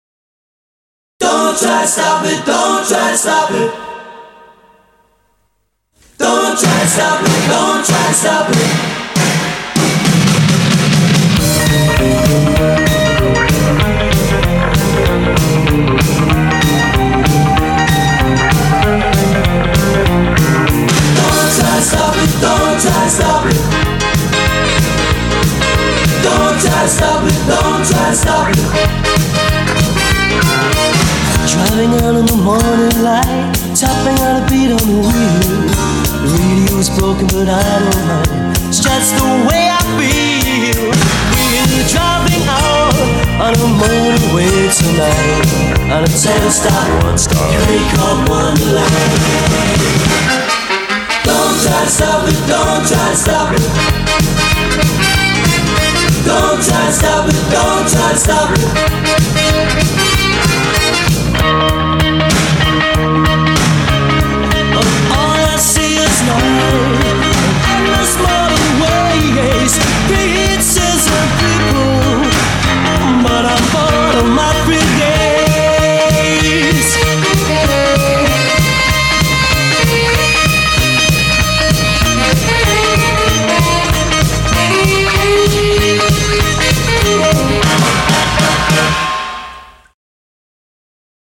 BPM191
Audio QualityPerfect (High Quality)
As the song implies, the groove doesn't stop!